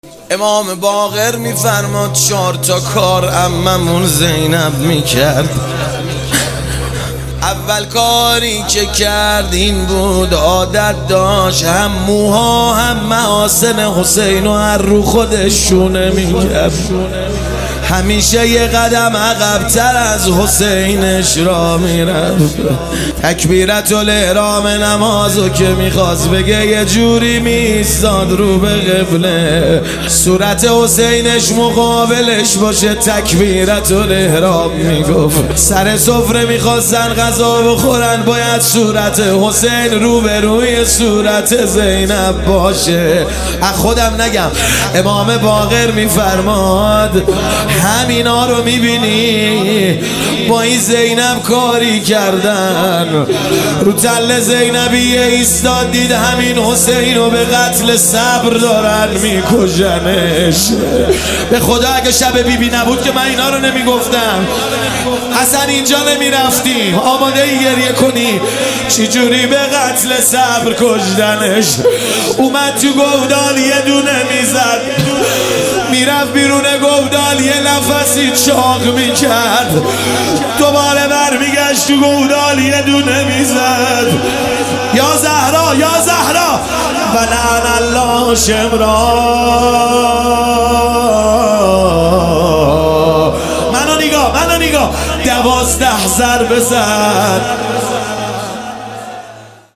روضه قتل صبر